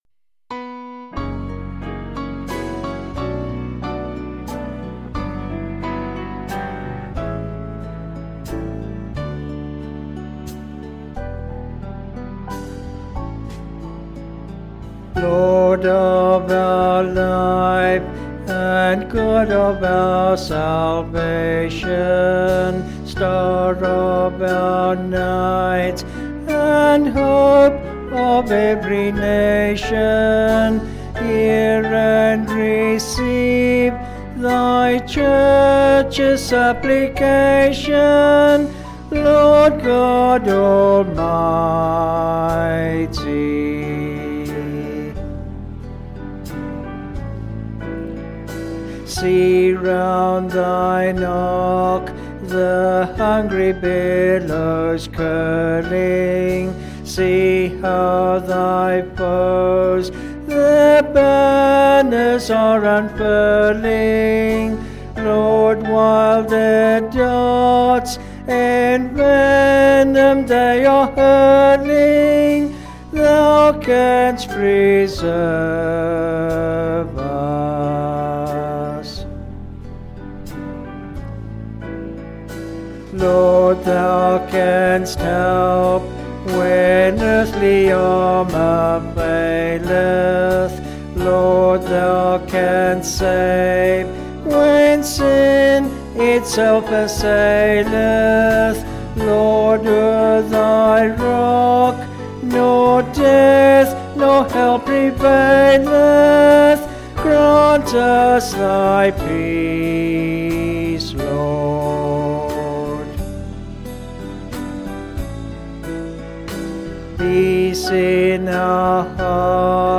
Vocals and Band
Sung Lyrics